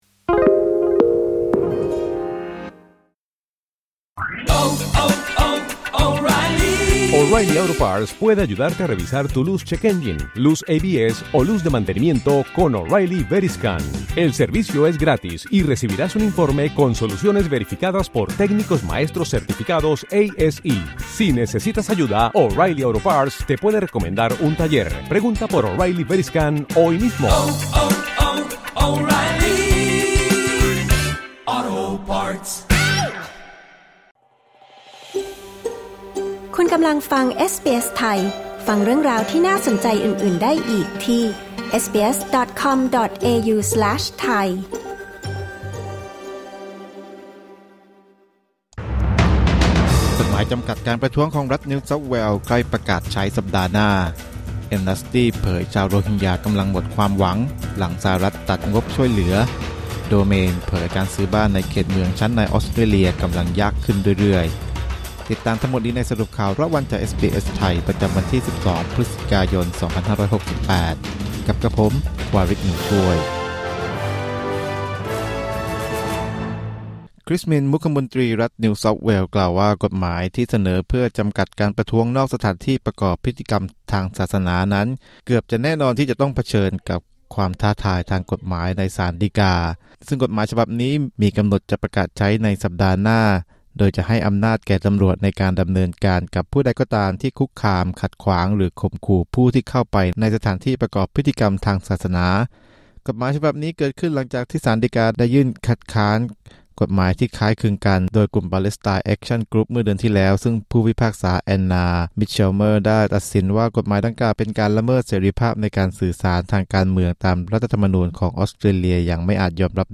สรุปข่าวรอบวัน 12 พฤศจิกายน 2568